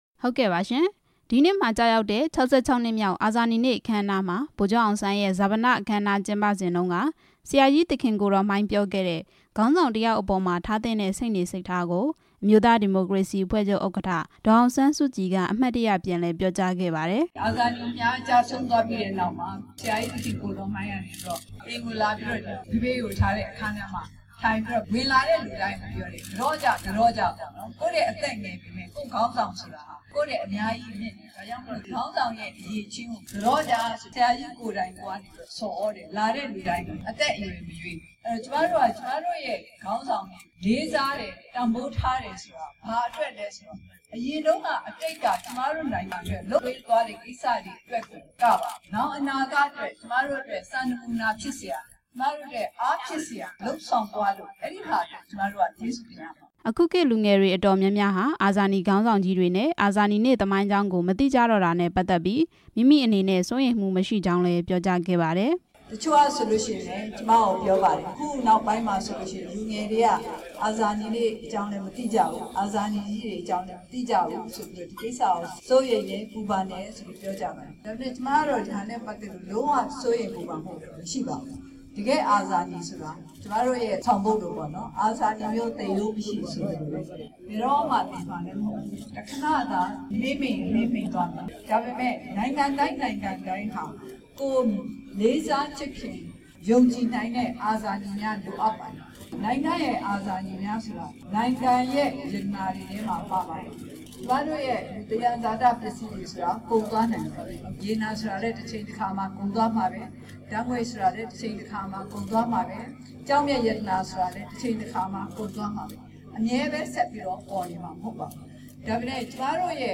ဒေါ်အောင်ဆန်းစုကြည်ရဲ့မိန့်ခွန်း ကောက်နှုတ်တင်ပြချက်
ဒီနေ့မှာကျရောက်တဲ့ ၆၆ နှစ်မြောက် အာဇာနည်နေ့ အထိမ်းအမှတ် အခမ်းအနားကို အမျိုးသားဒီမိုကရေစီ အဖွဲ့ချုပ်ရုံးချုပ်မှာ ကျင်းပရာမှာ ရုံးချုပ်ရှေ့မှာ စောင့် ဆိုင်းနေကြတဲ့ လူငယ်တွေနဲ့ ပြည်သူတွေ ကို ဒေါ်အောင်ဆန်းစုကြည်က ပြောခဲ့တာပါ။